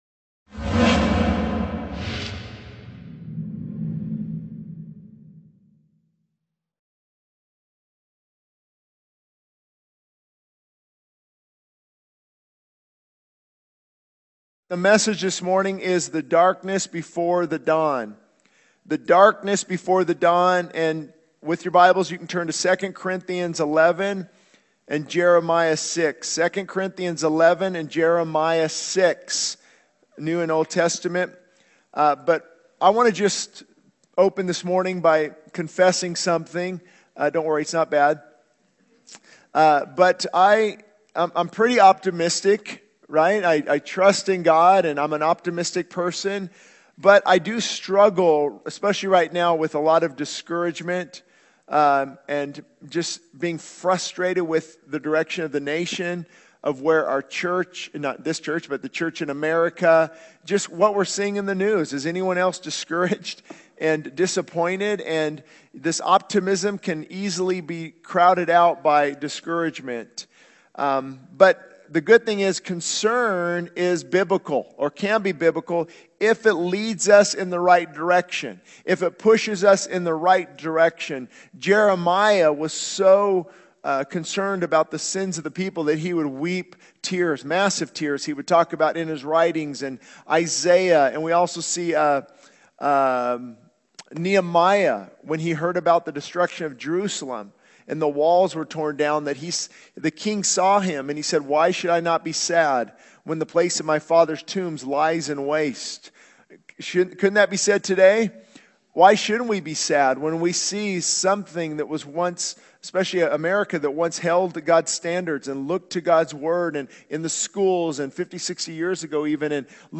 This sermon emphasizes the darkness before the dawn, highlighting the struggle with discouragement and frustration in the face of societal challenges. It calls for righteous indignation and action in response to the moral decline in the nation. The message reminds believers that darkness is seasonal, drawing parallels to the victory over darkness through Christ's crucifixion and resurrection.